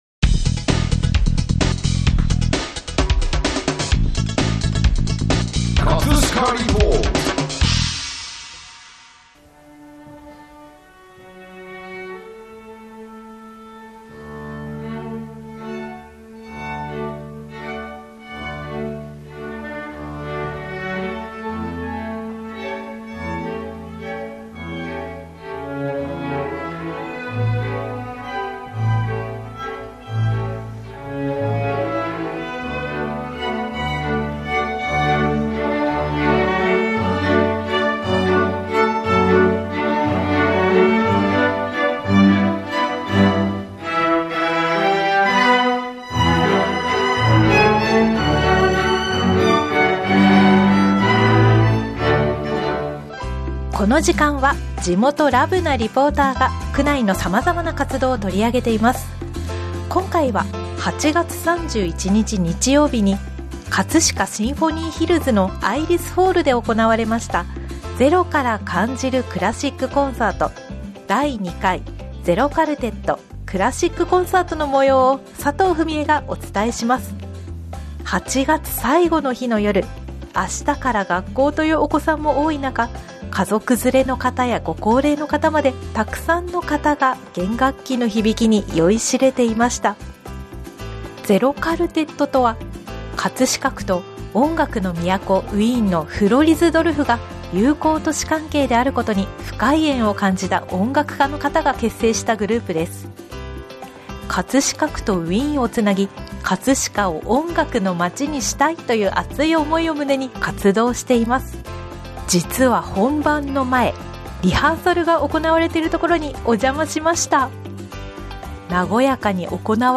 【葛飾リポート】 葛飾リポートでは、区内の様々な活動を取り上げています。
葛飾リポートでは、ZEROカルテットの皆さんによる座談会の模様をお送りします！